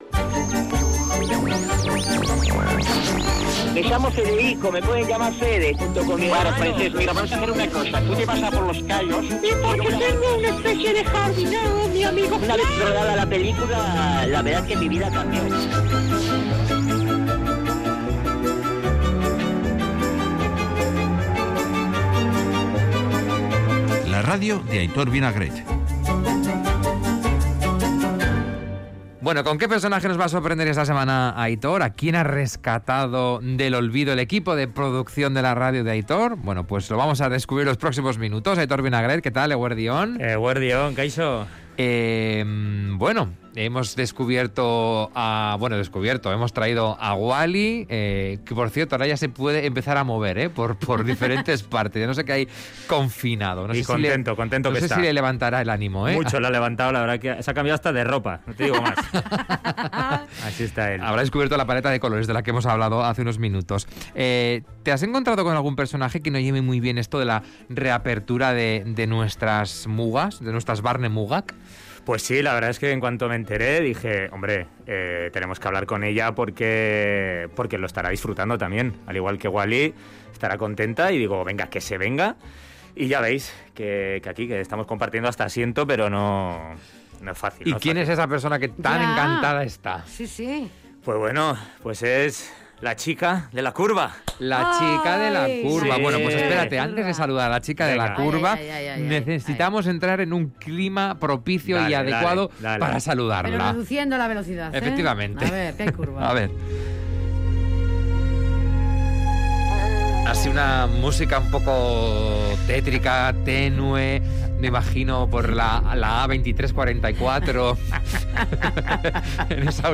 Entrevista con la niña de la curva, advierte que cambiará de lugar